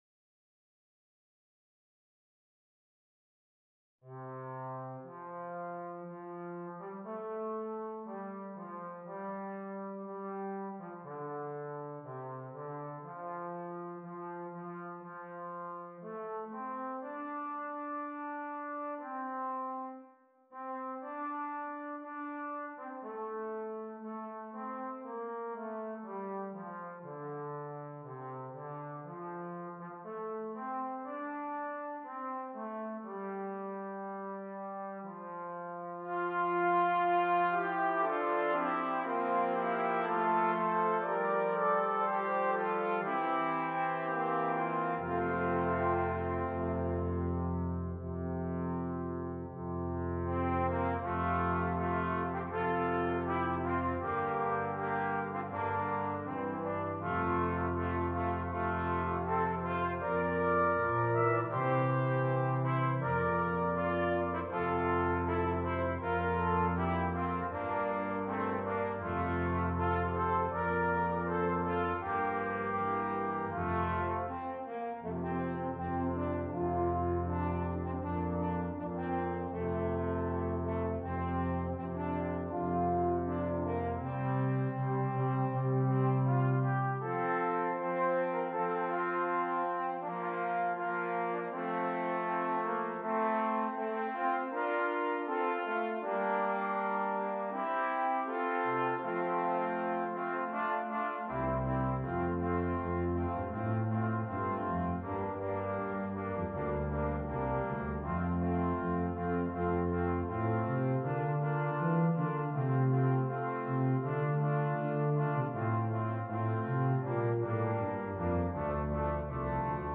Brass Quintet
Traditional